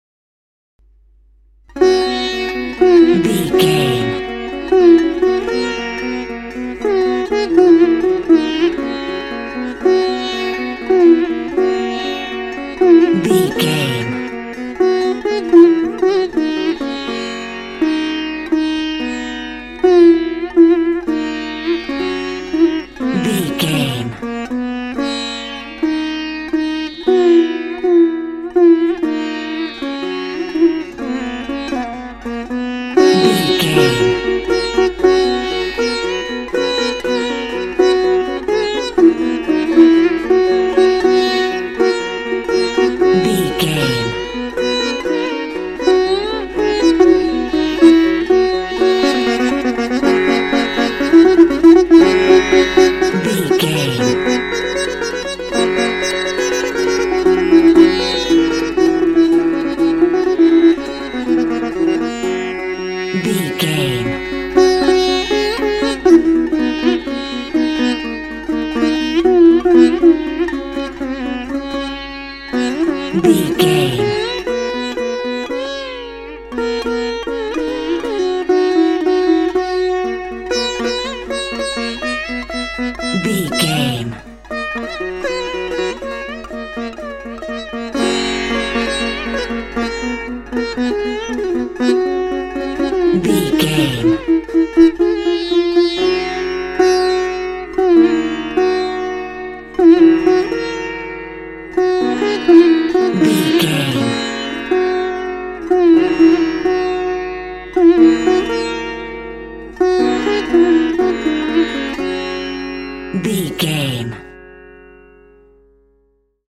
Ionian/Major
G♭
World Music
percussion